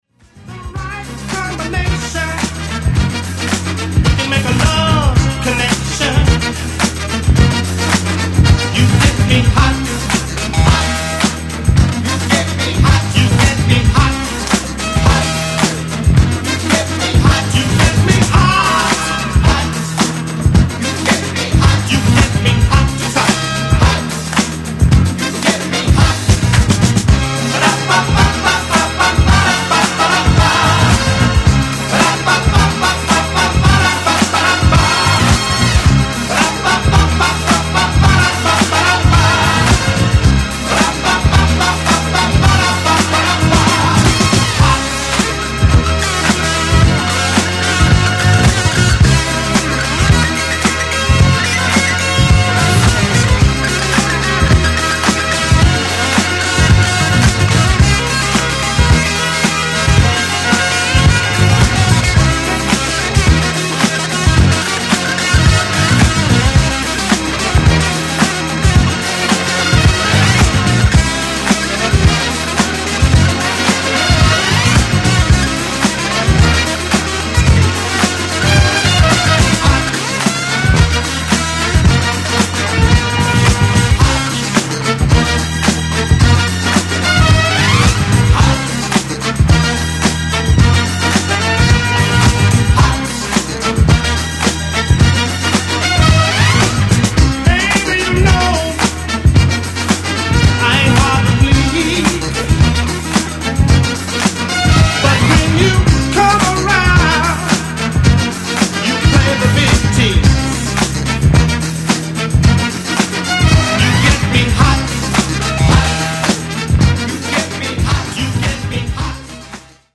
ジャンル(スタイル) NU DISCO